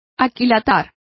Complete with pronunciation of the translation of assayed.